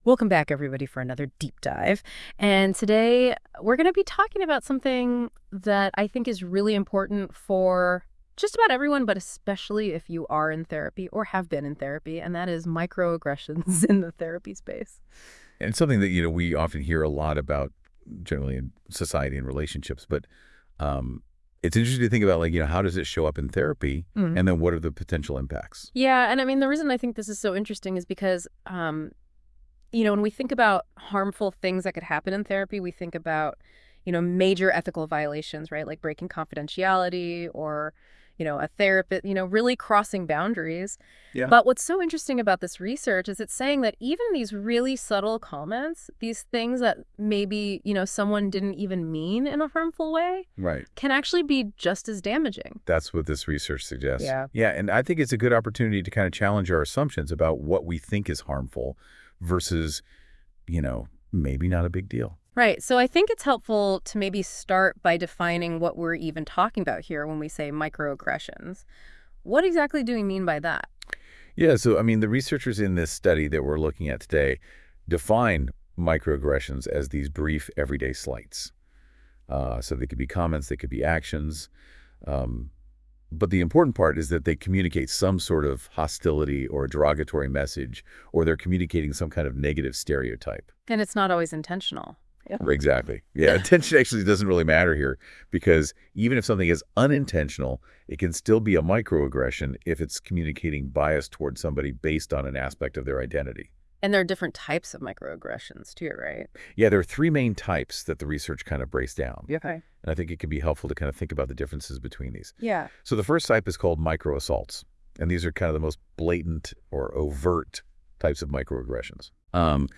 This podcast was generated by Notebook LM and reviewed by our team, please listen with discretion. The purpose of this study was to explore how microaggressions affect counseling outcomes. Participants were 128 racial/ethnic minority individuals who had been in counseling within the past year.